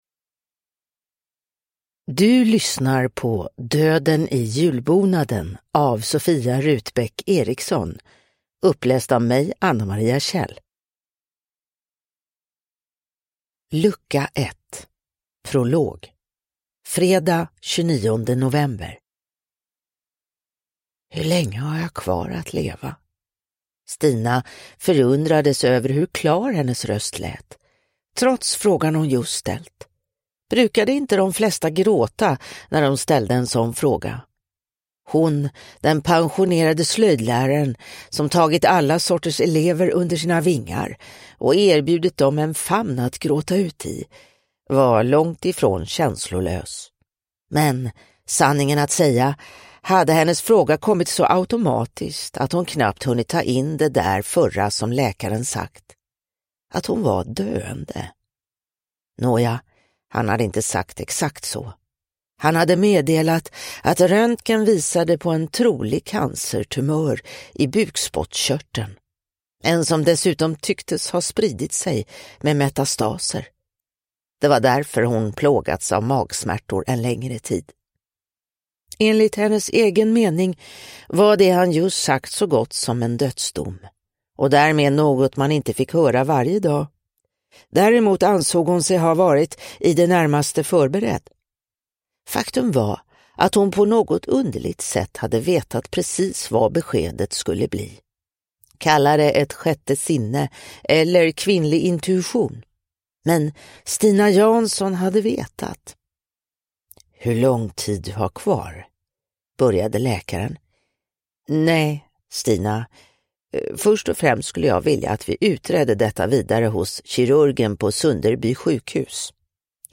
Döden i julbonaden: Lucka 1 – Ljudbok